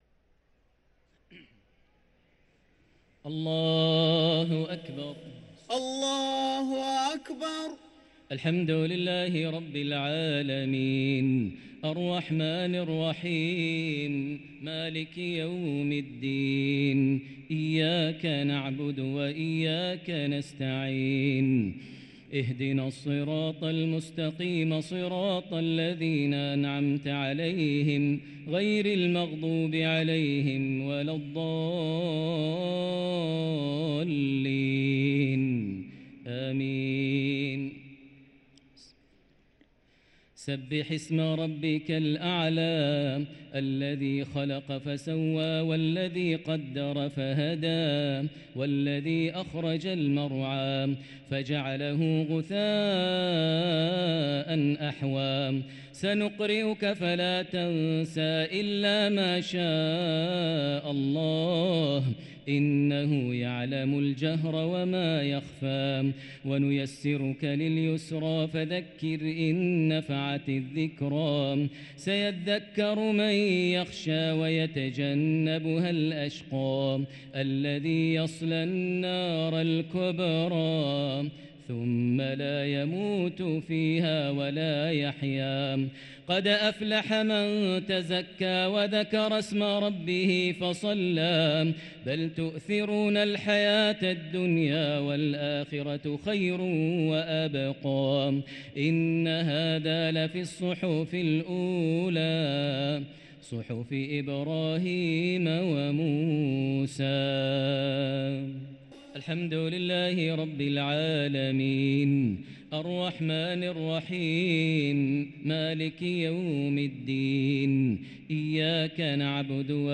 صلاة التراويح ليلة 11 رمضان 1444 للقارئ ماهر المعيقلي - الشفع والوتر - صلاة التراويح